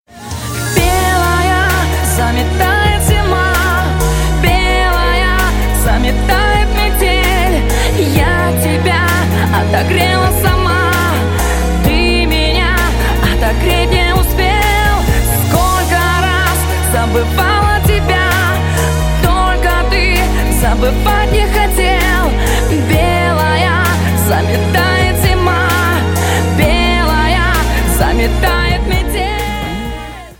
• Качество: 320, Stereo
поп
красивые
женский вокал
грустные
спокойные